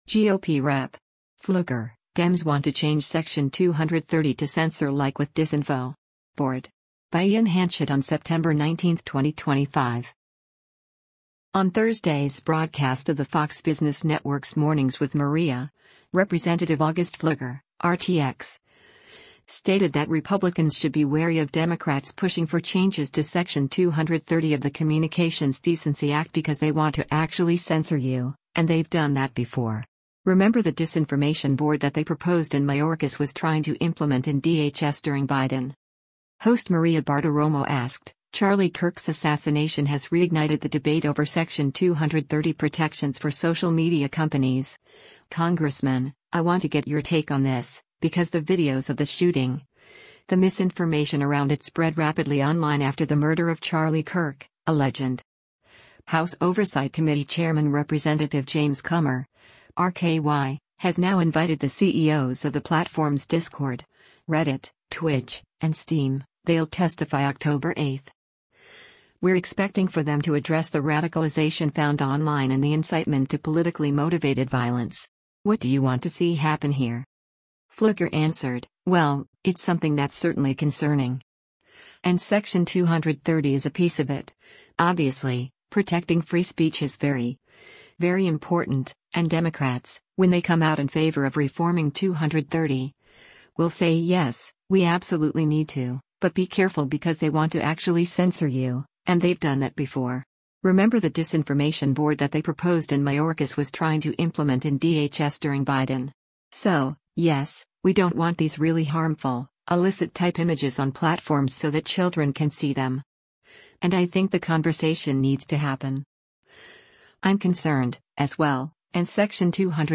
On Thursday’s broadcast of the Fox Business Network’s “Mornings with Maria,” Rep. August Pfluger (R-TX) stated that Republicans should be wary of Democrats pushing for changes to Section 230 of the Communications Decency Act “because they want to actually censor you, and they’ve done that before. Remember the Disinformation Board that they proposed and Mayorkas was trying to implement in DHS during Biden.”